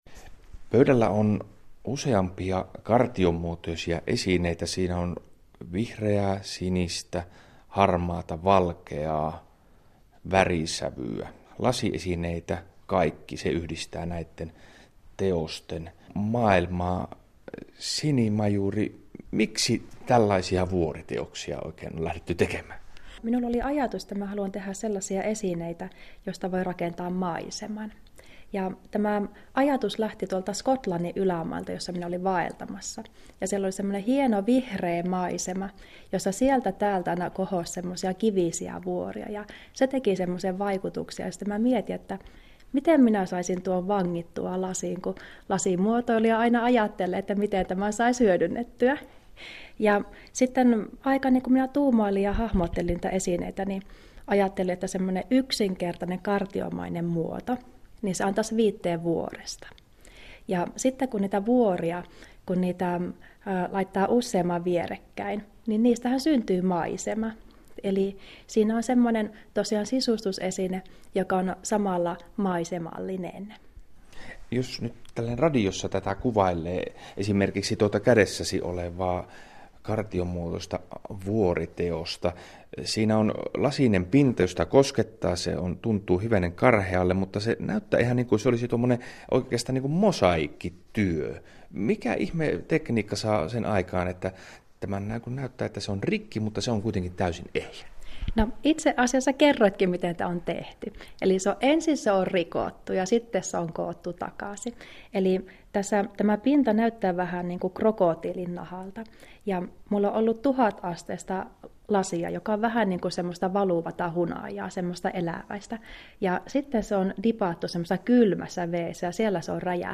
Interview in Radio Suomi